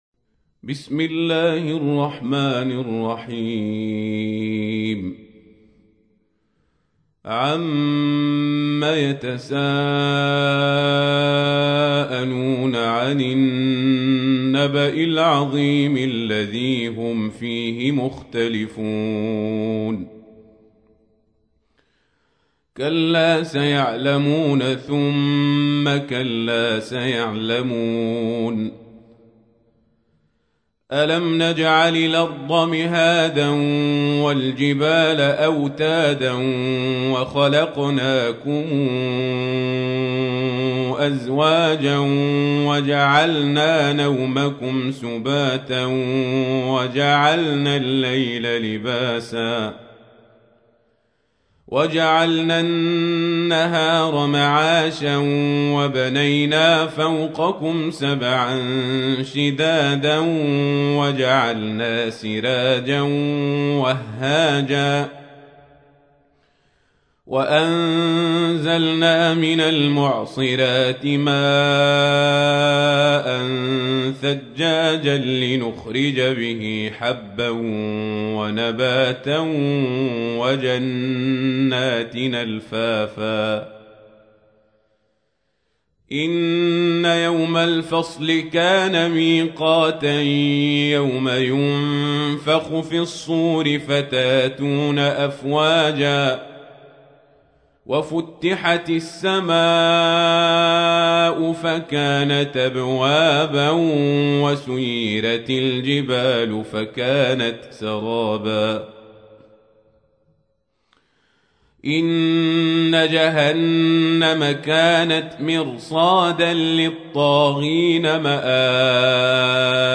تحميل : 78. سورة النبأ / القارئ القزابري / القرآن الكريم / موقع يا حسين